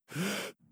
Breath Start.wav